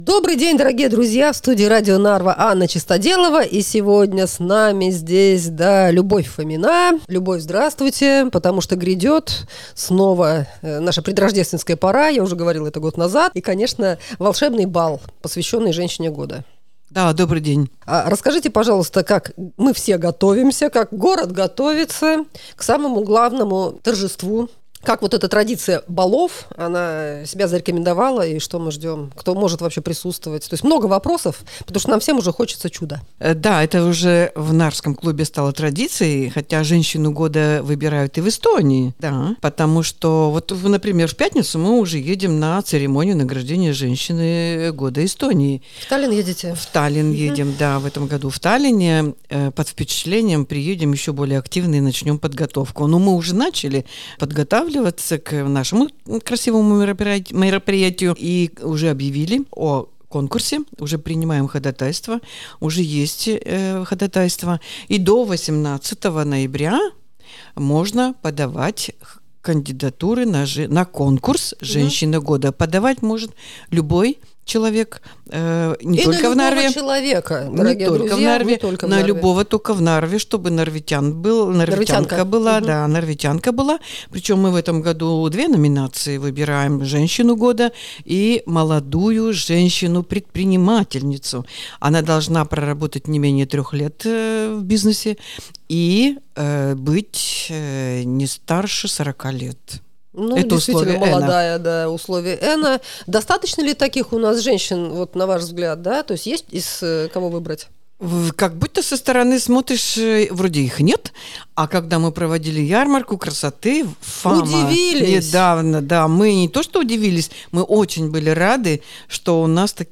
7 декабря в Ranna Resto состоится церемония вручения премии Женщины года, учрежденной клубом деловых и предприимчивых женщин EENA. Подробности - в интервью